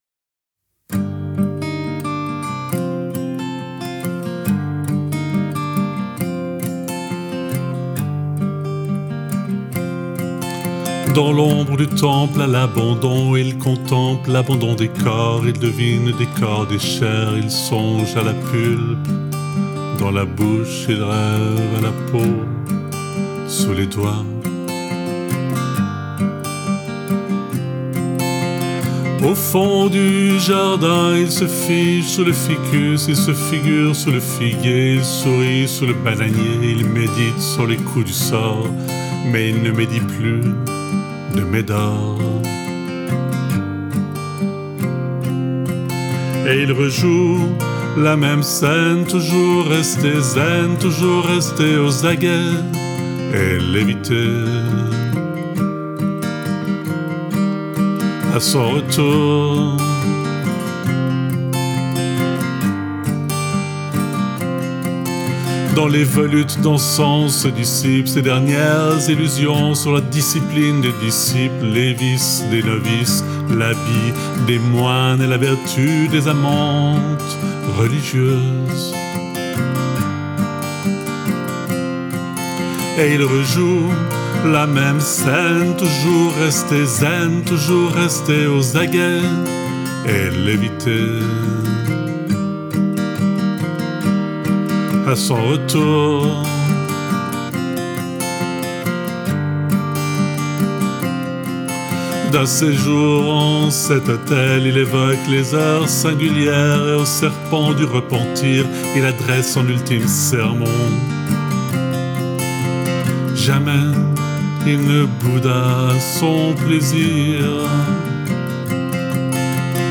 guitare, voix